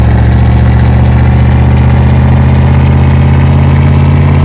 Index of /server/sound/vehicles/lwcars/quadbike